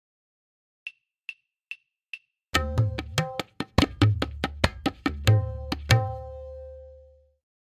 9-Matra Mukhra
M8.5-Mukhra-1x-Click.mp3